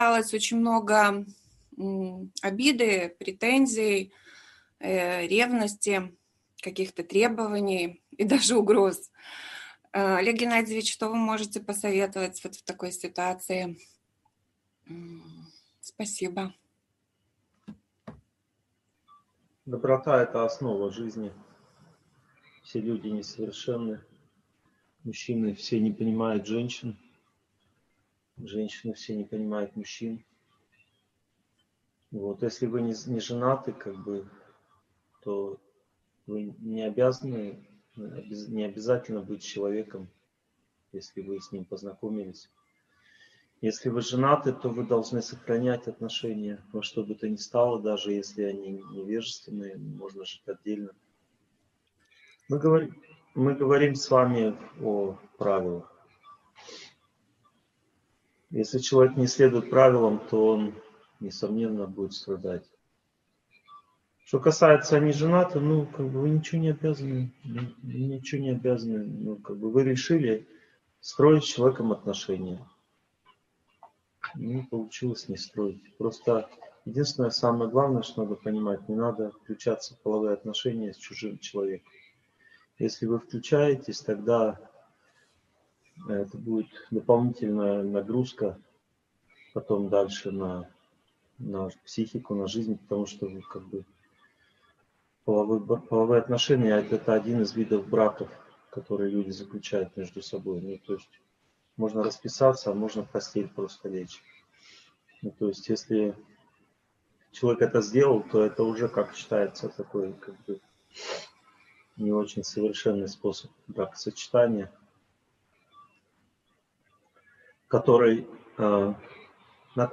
Актуальные вопросы взаимоотношений мужчины и женщины (онлайн-семинар, 2020)